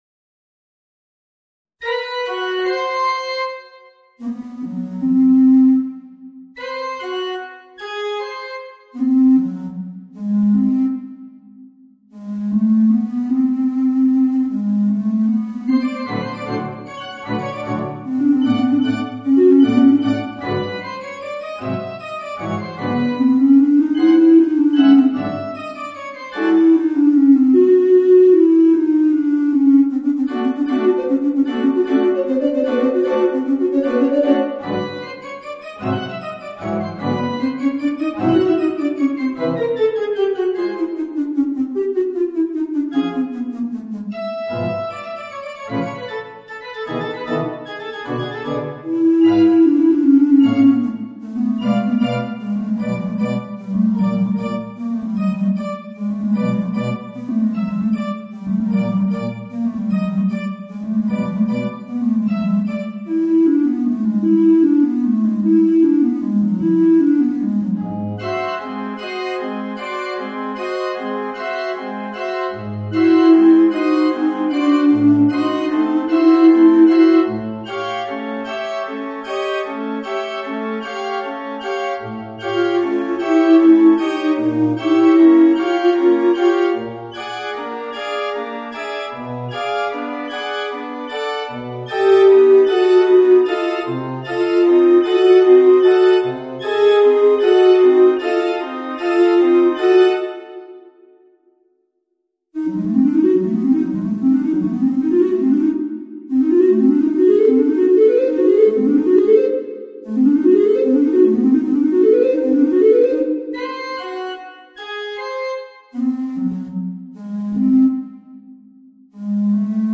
Voicing: Bass Recorder and Piano